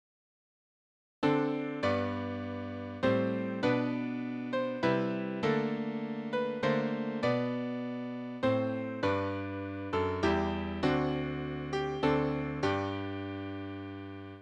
Fragment of a “Moresca” (Dance) from Monteverde’s “Orfeo” (1609).]